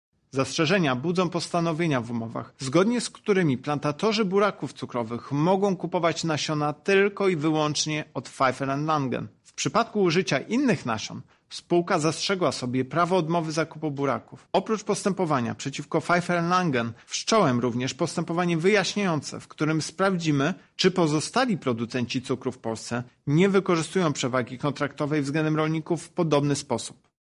• wyjaśnia Tomasz Chróstny, prezes UOKiK